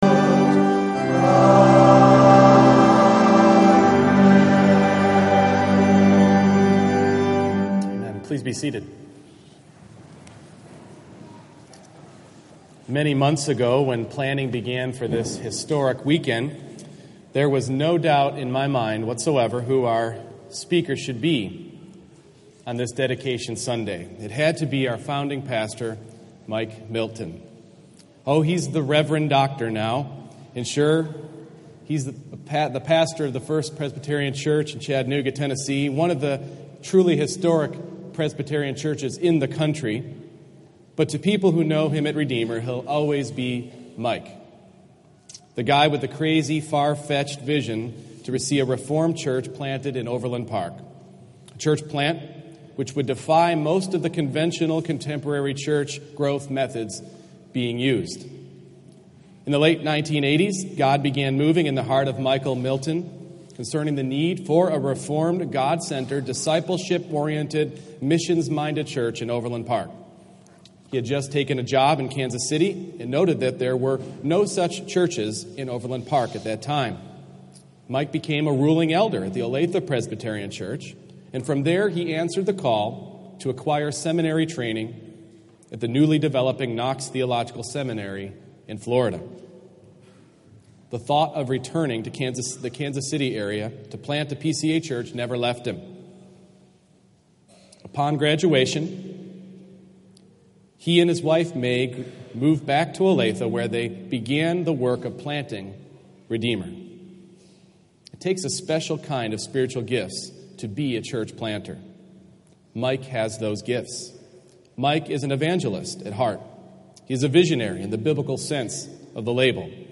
Church Dedication Service